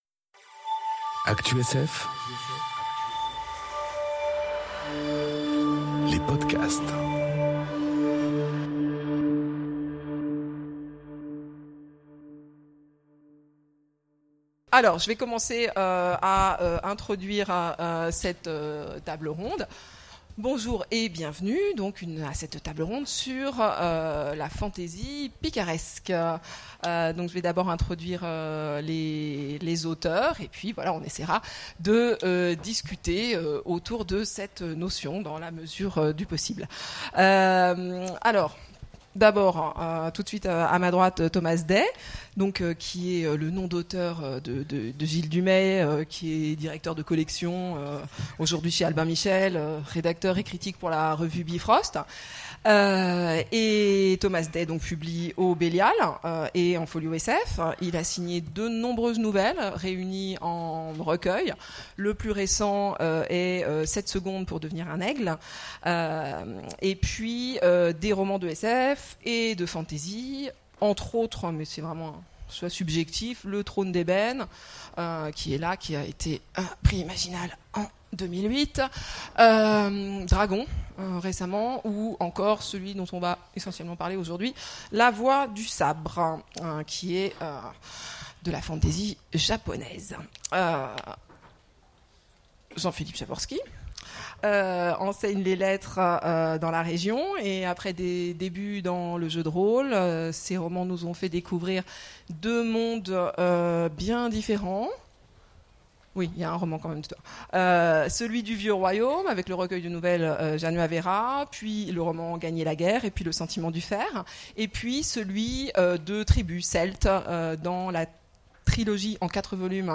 Imaginales 2018 : Conférence Ecrire de la fantasy... Et faire le choix du picaresque !